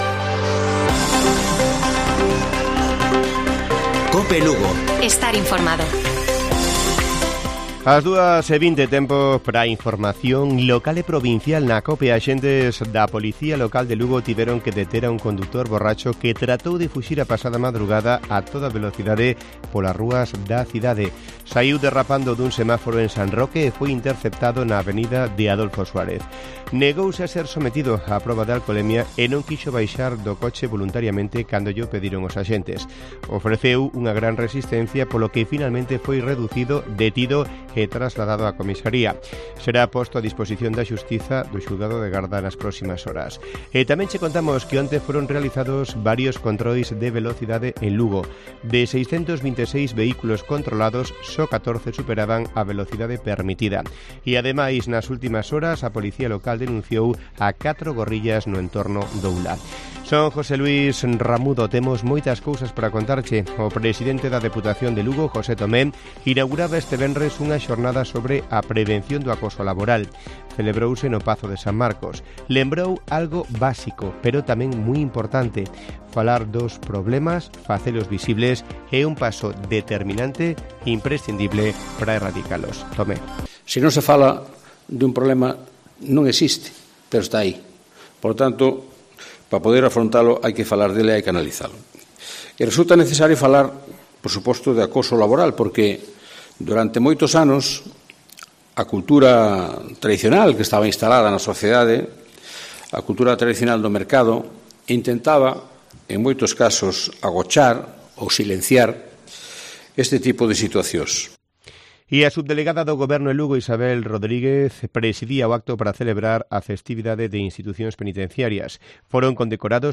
Informativo Mediodía de Cope Lugo. 23 DE SEPTIEMBRE. 14:20 horas